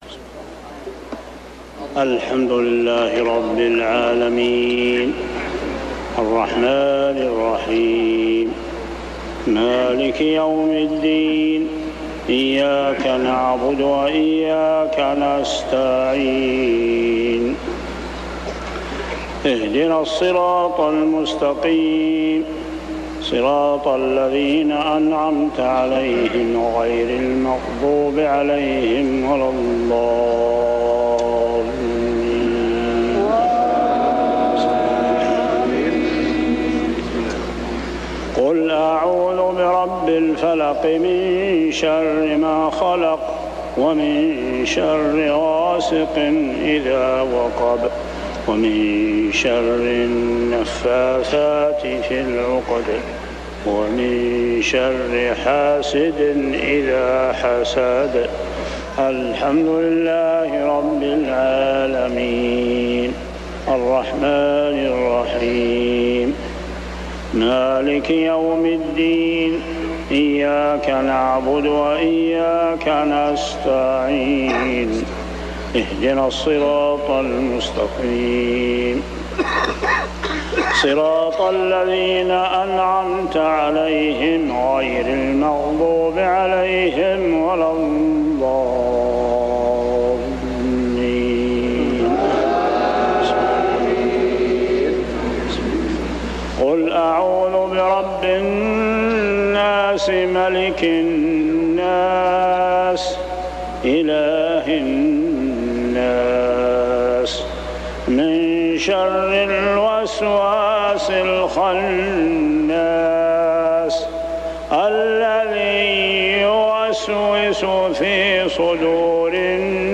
صلاة المغرب 6-9-1402هـ سورتي الفلق و الناس كاملة | maghrib prayer Surah Al-Falaq and An-Nas > 1402 🕋 > الفروض - تلاوات الحرمين